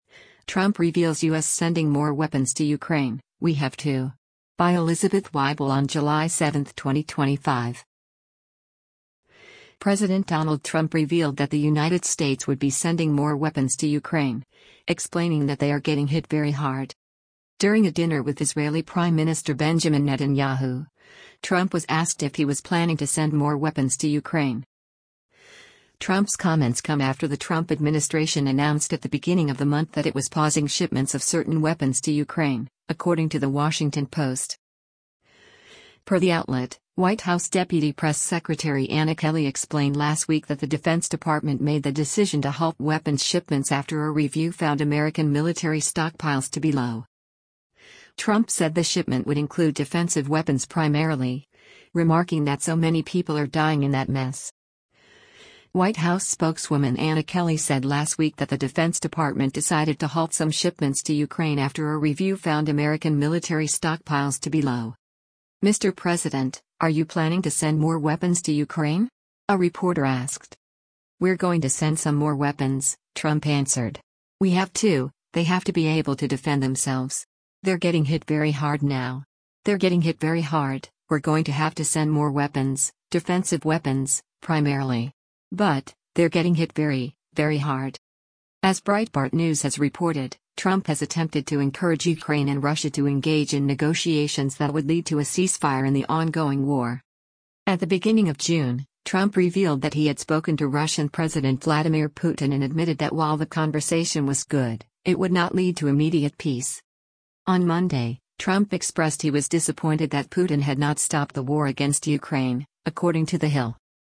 During a dinner with Israeli Prime Minister Benjamin Netanyahu, Trump was asked if he was “planning to send more weapons to Ukraine.”
“Mr. President, are you planning to send more weapons to Ukraine?” a reporter asked.